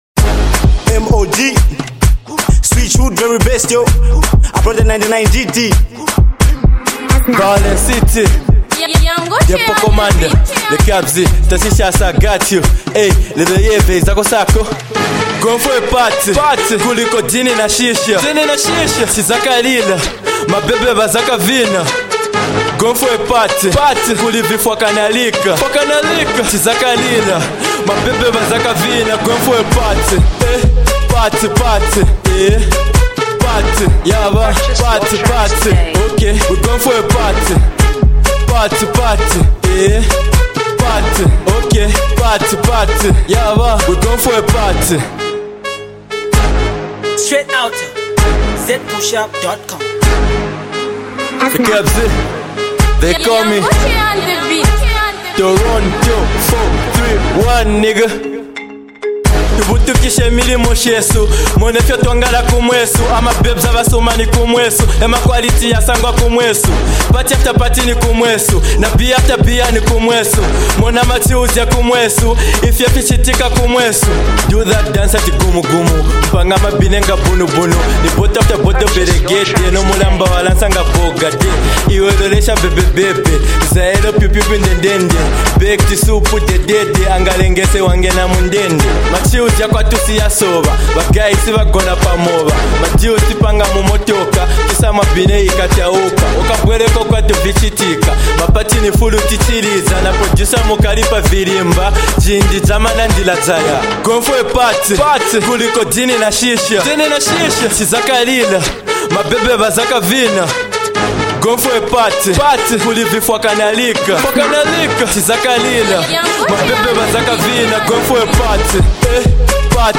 festival dancehall track